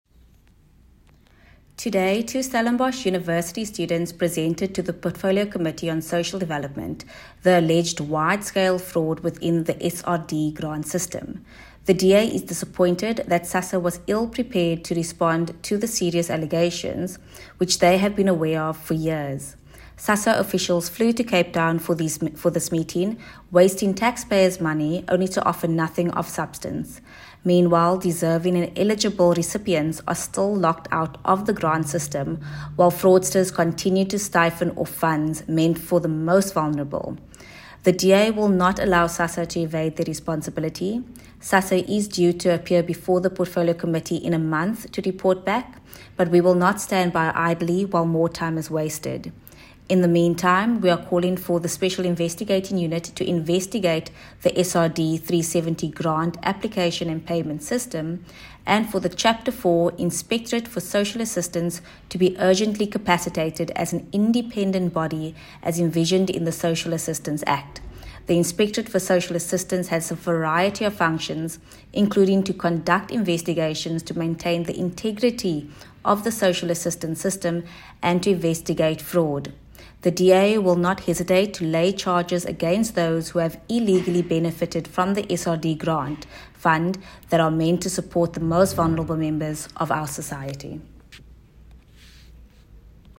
soundbite by Alexandra Abrahams MP.